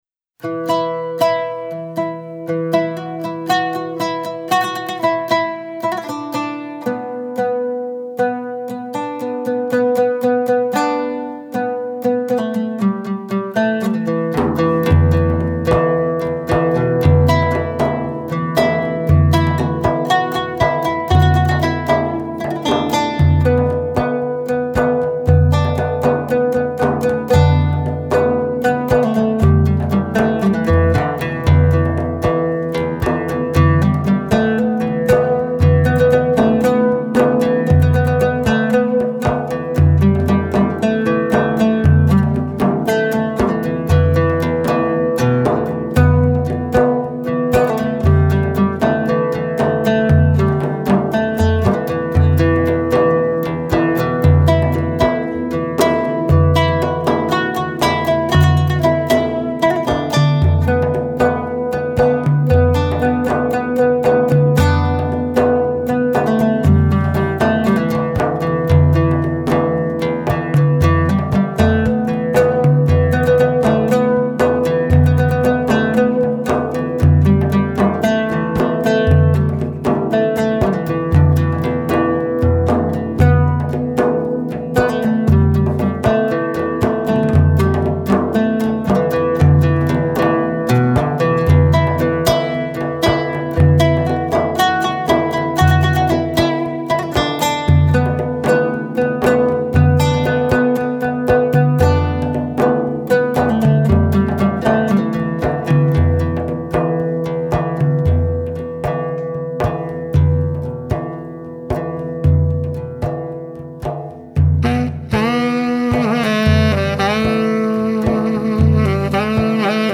Album outtake